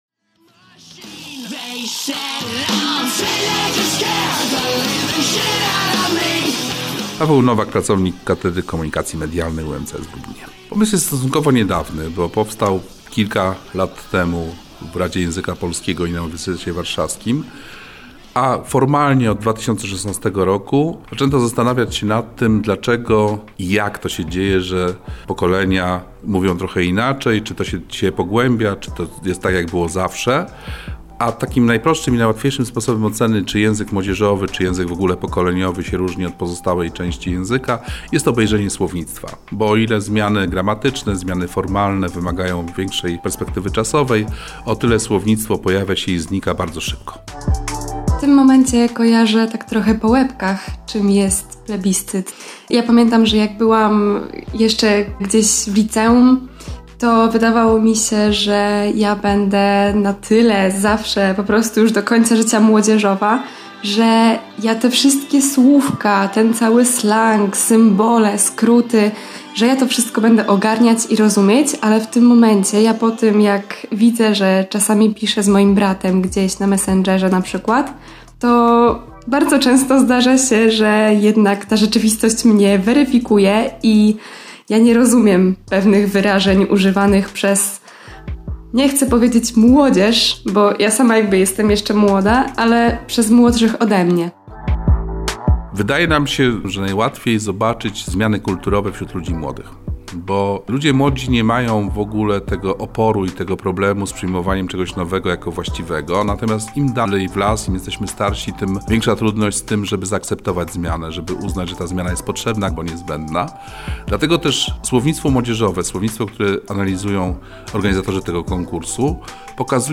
oraz młode pokolenie we własnej osobie.